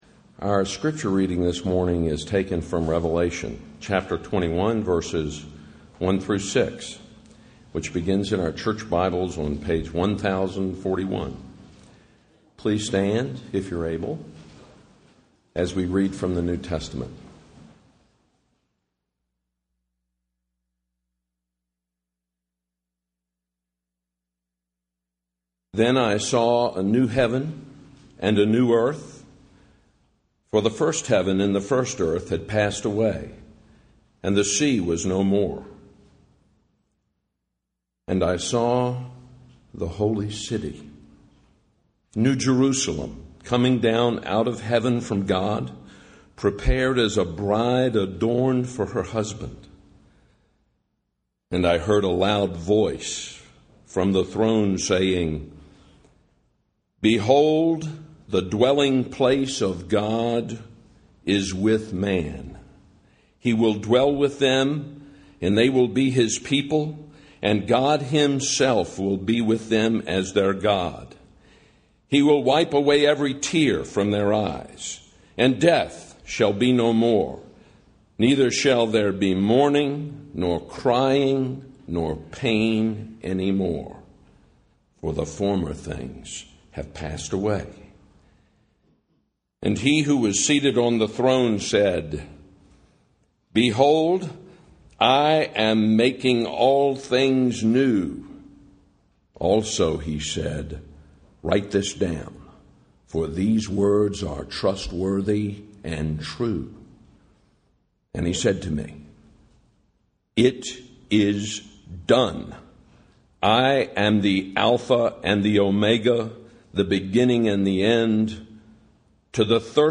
sermon-audio-9.1.13.mp3